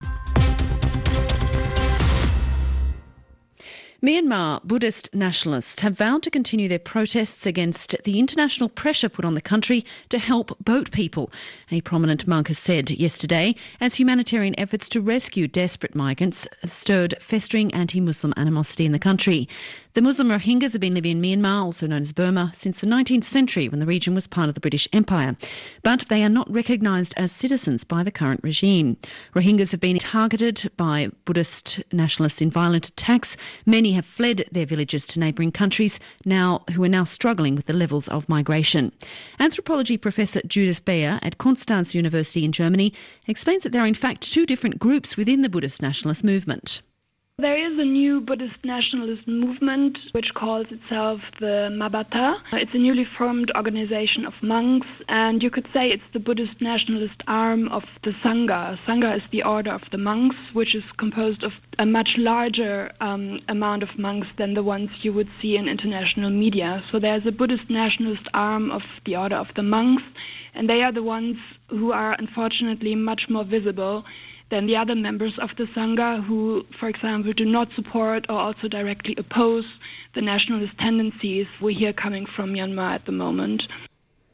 Radio Interview for Radio France International.